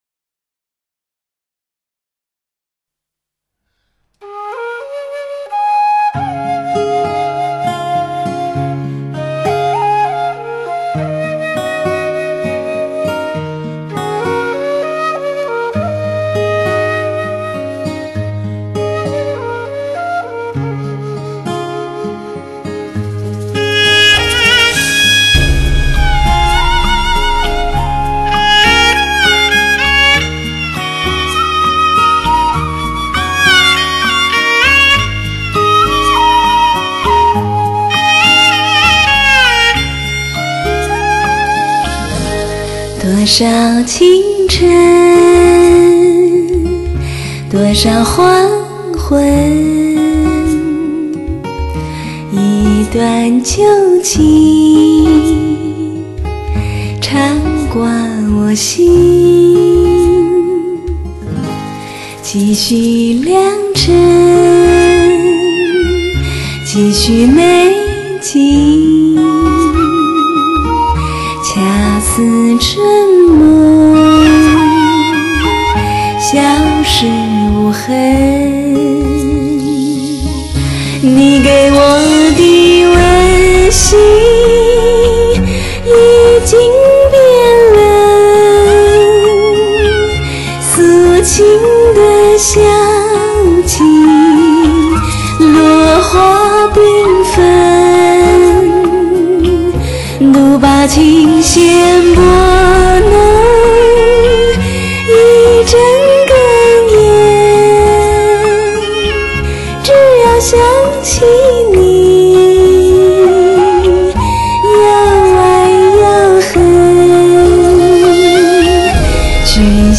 2007年最干净的女音，娇媚性感。